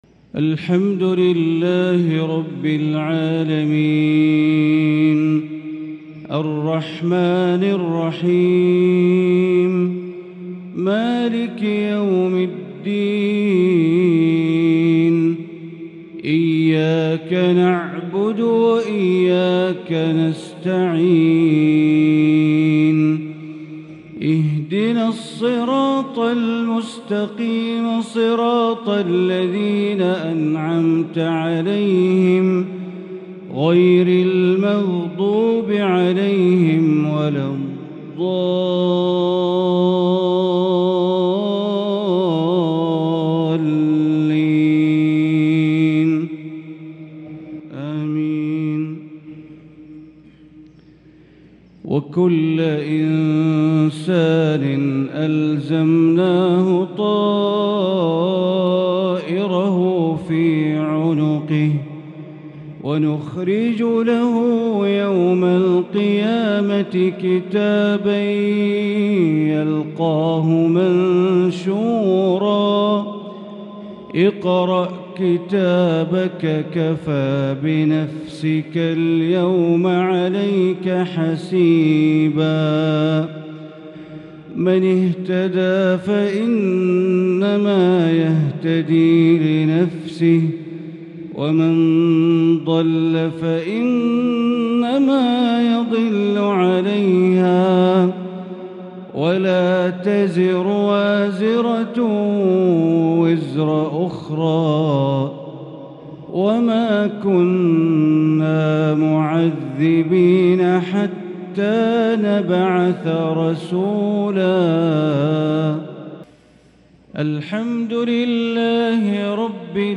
صلاة المغرب 3 شوال 1443هـ من سورة الإسراء | Maghrib prayer from Surah Al-Israa’ 4-5-2022 > 1443 🕋 > الفروض - تلاوات الحرمين